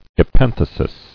[e·pen·the·sis]